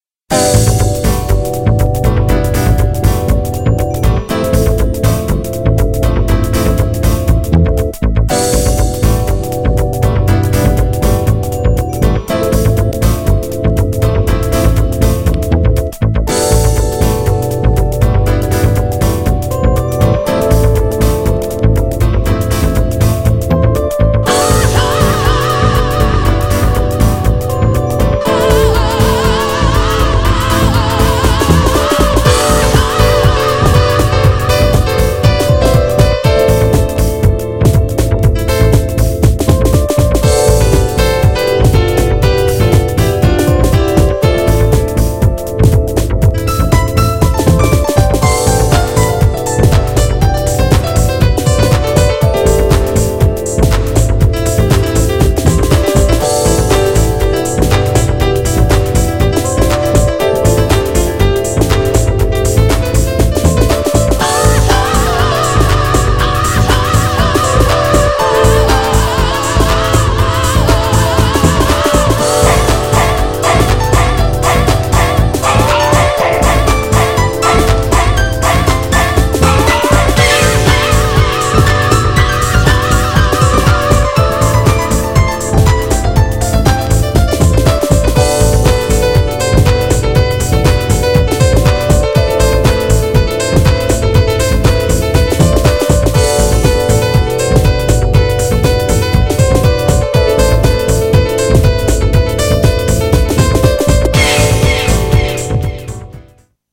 これまでの同レーベルの音に比べ、垢抜けた感じがするドラマティックなボーカルHOUSE!!
GENRE House
BPM 116〜120BPM